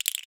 Index of /90_sSampleCDs/Roland - Rhythm Section/PRC_Latin 3/PRC_Castanets